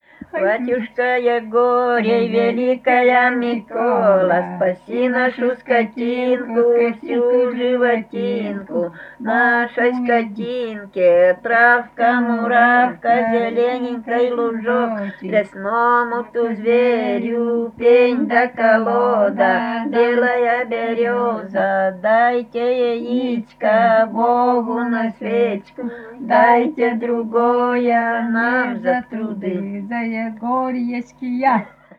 Это очевидно связано с одинаковыми условиями исполнения (на открытом воздухе) и призывной активной интонацией, с которой исполняются все календарные жанры в костромской традиции.
01 Егорьевская песня «Батюшка Егорий, великая Микола» в исполнении жительниц д. Копьёво Кологривского района Костромской области